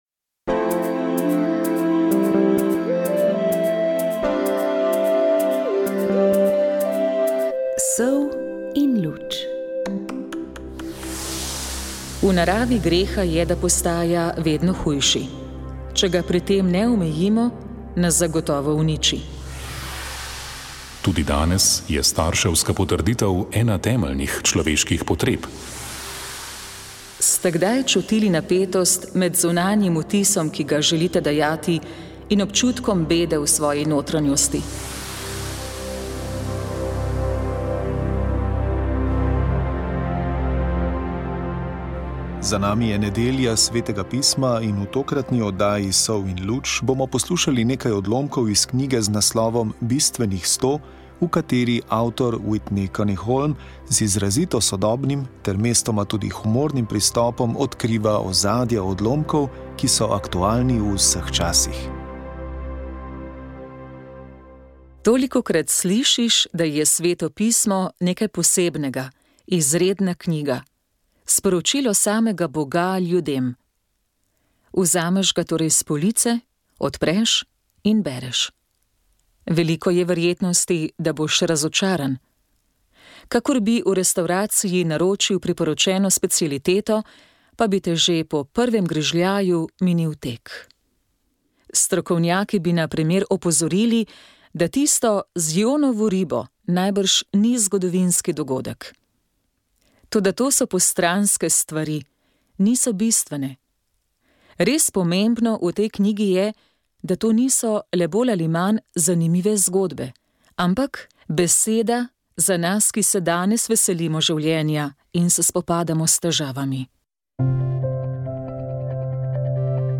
Za nami je nedelja Svetega pisma in v tokratni oddaji Sol in luč smo poslušali nekaj odlomkov iz knjige z naslovom Bistvenih sto v kateri avtor, Whitney Kuniholm z izrazito sodobnim, ter mestoma tudi humornim pristopom odkriva ozadja odlomkov, ki so aktualni v vseh časih.